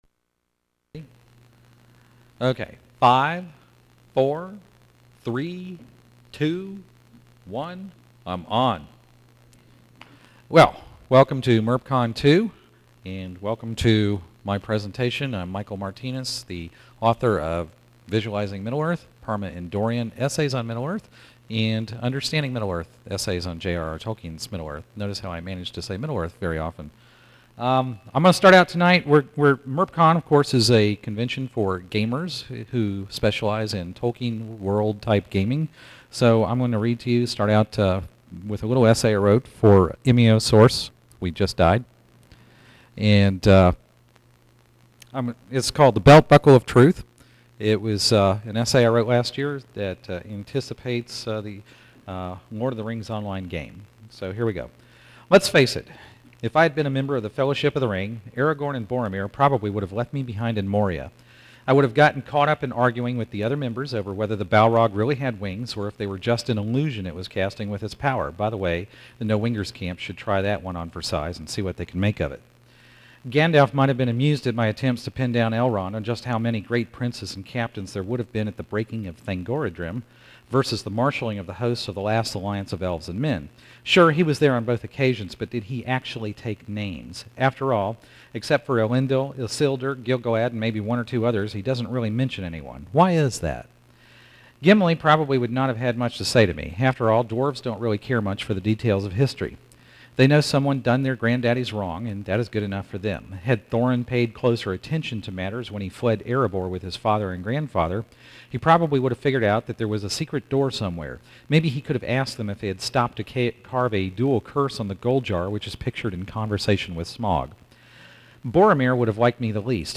MerpCon 2
Speech